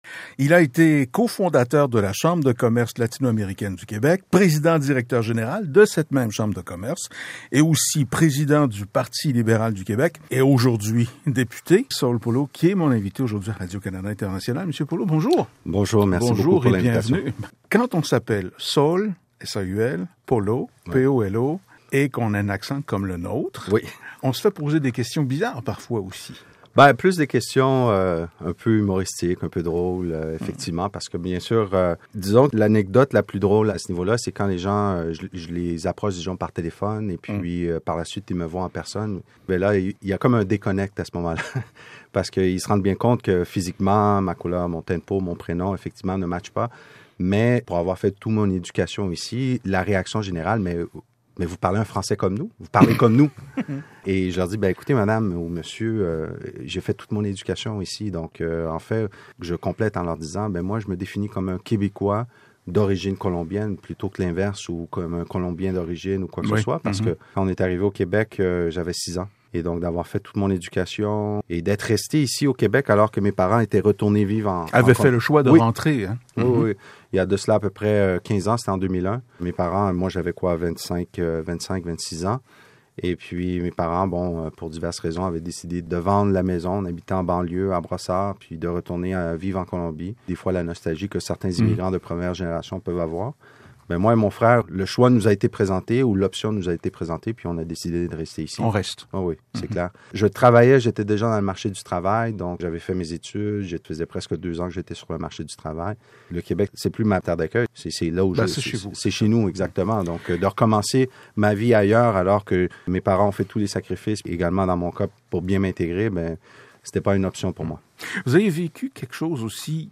Voici notre conversation complète, émaille de musique et de paix intérieure